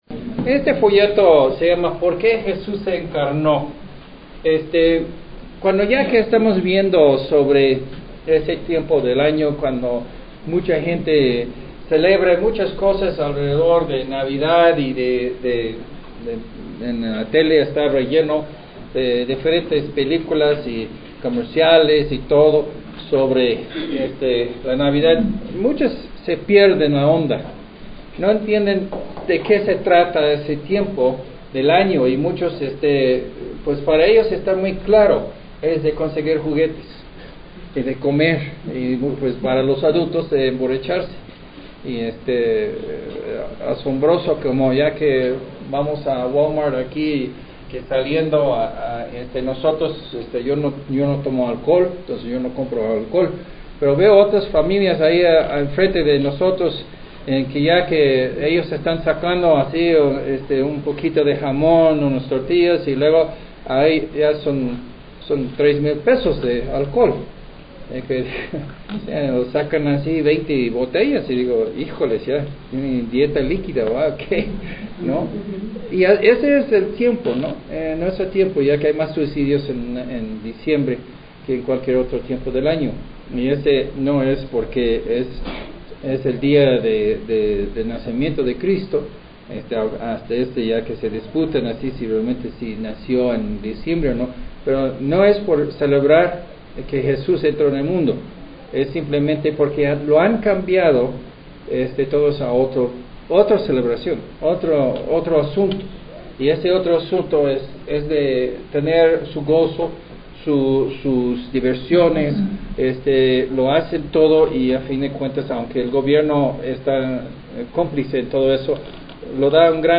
Sermón en Audio